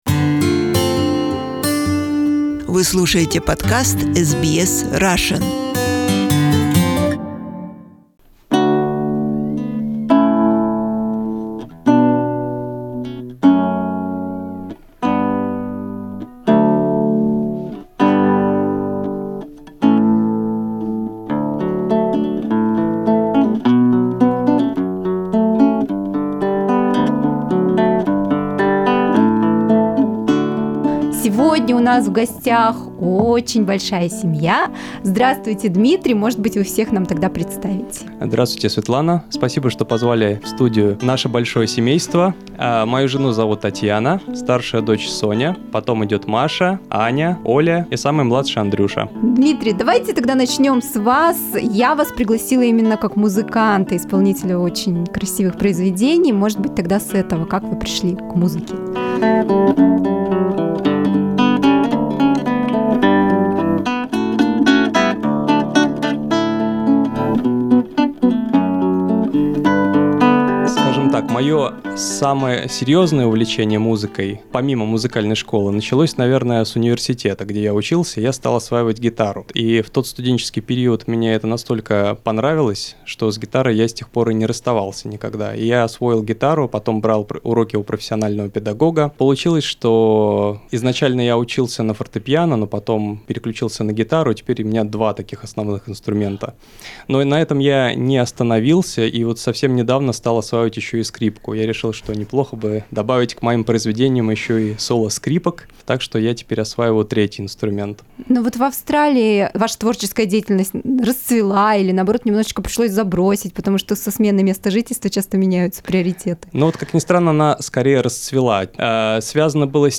заглянули к нам в студию, чтобы поделиться секретами своей эффективности.